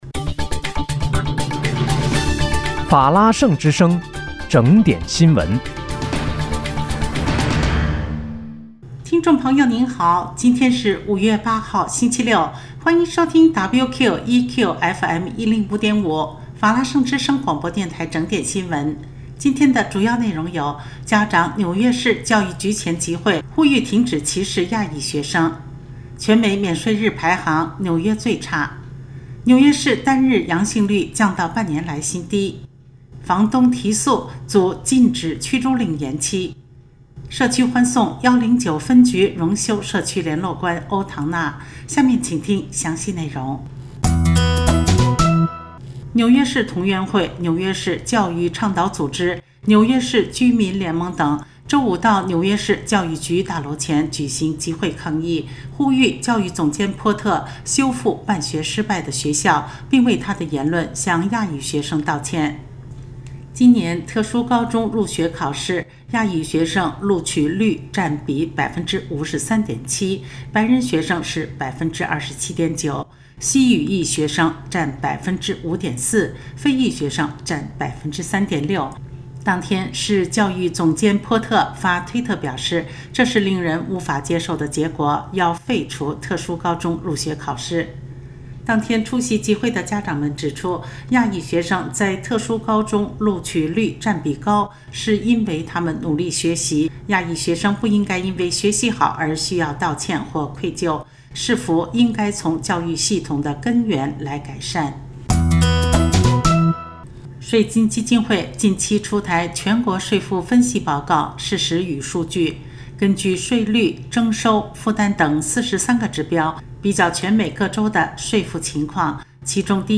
5月8日（星期六）纽约整点新闻